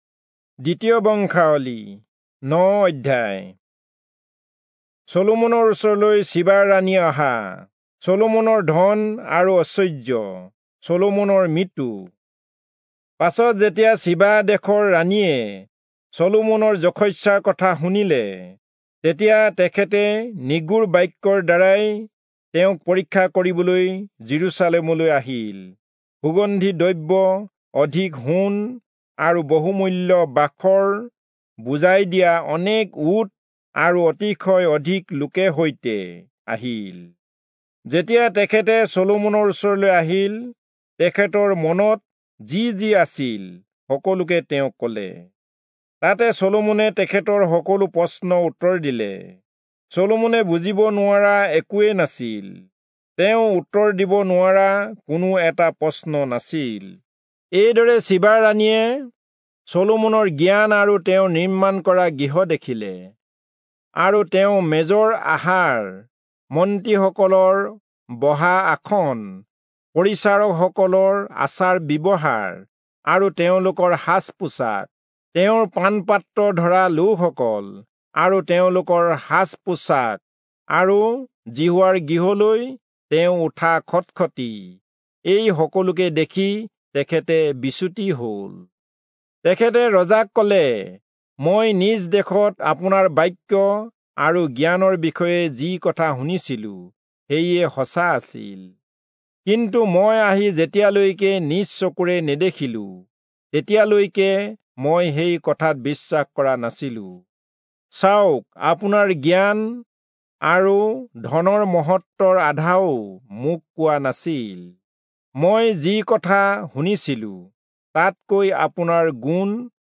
Assamese Audio Bible - 2-Chronicles 8 in Urv bible version